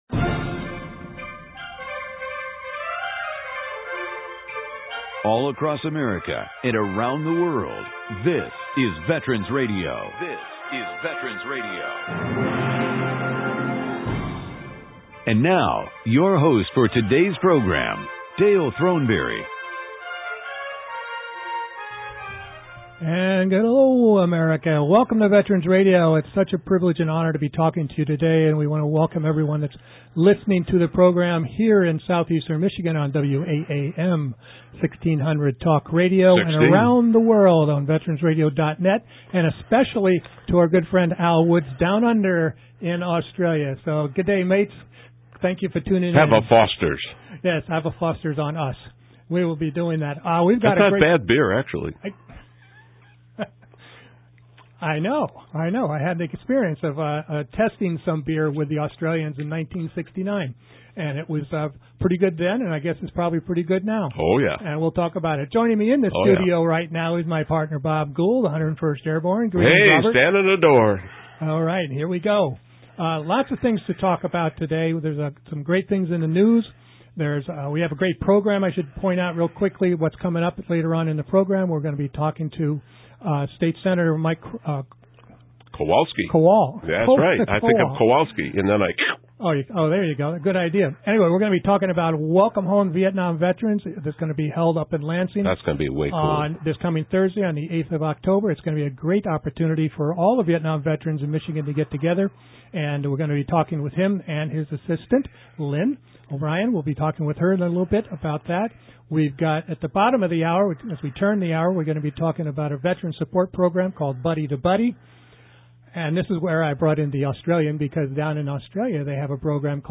Michigan Senate Majority Floor Leader Mike Kowall reminds Michigan residents that he is sponsoring an event honoring veterans of the Vietnam War era on the Capitol lawn on Thursday, Oct. 8. Veterans Radio welcomes Senator Kowall to our studios at WAAM this Sunday and we encourage all Vietnam Era Veterans and their families to attend.